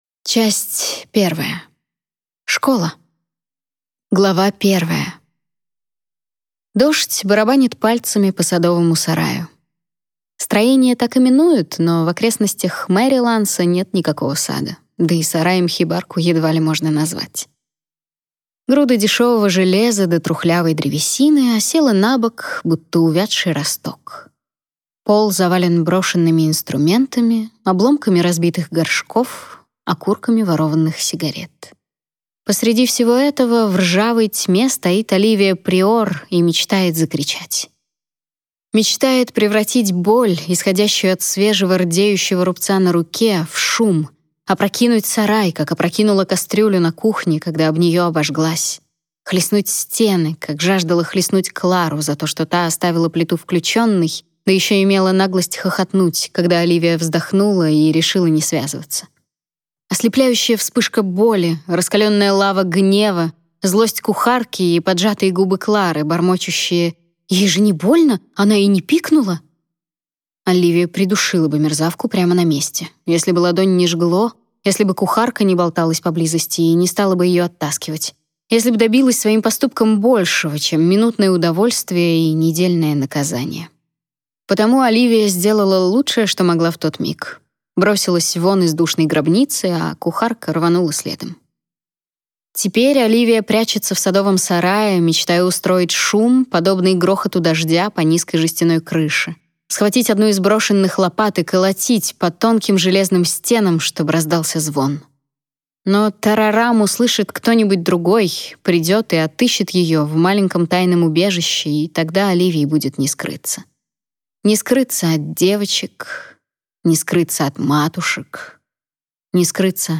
Аудиокнига Галлант | Библиотека аудиокниг